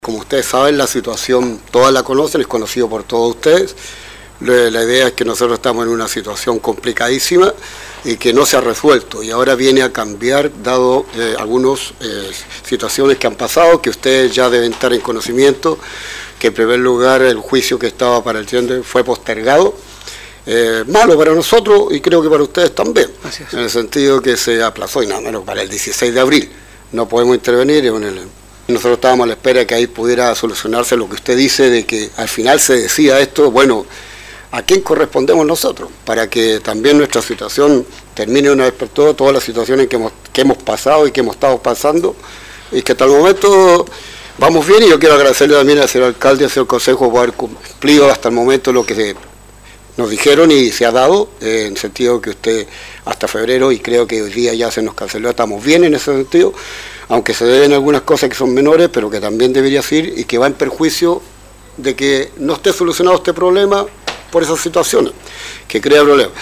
ante el concejo municipal, en una reunión ordinaria que tuvo lugar la semana pasada